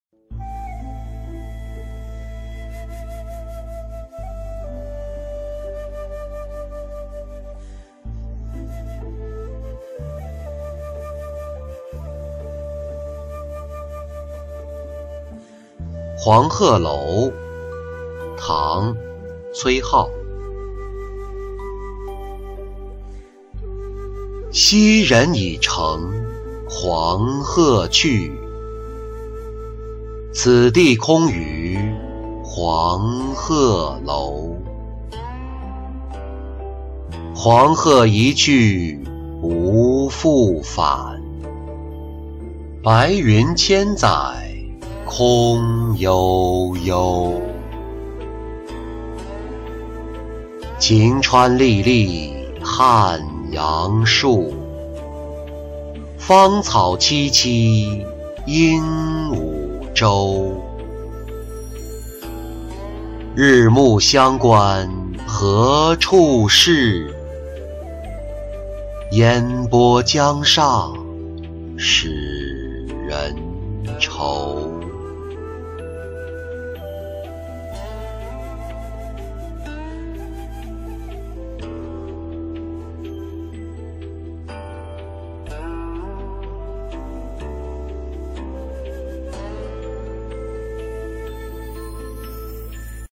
黄鹤楼-音频朗读